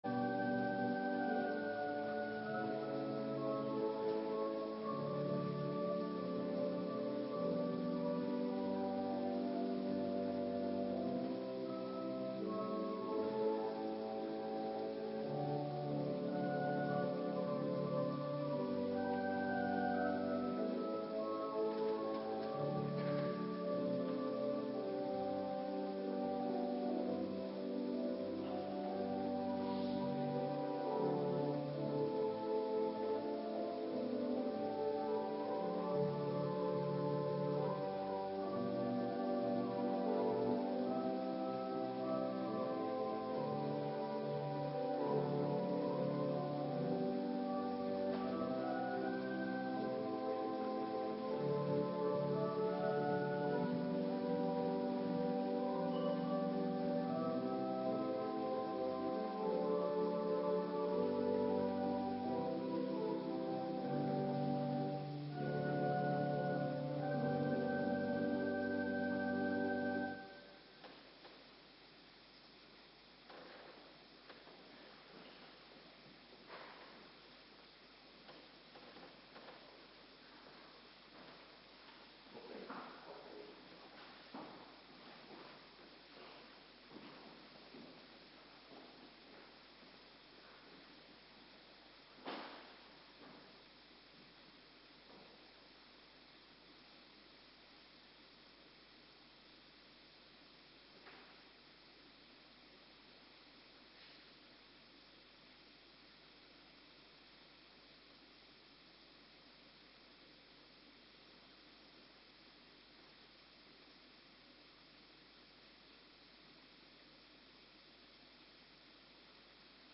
Avonddienst - Cluster 1
Locatie: Hervormde Gemeente Waarder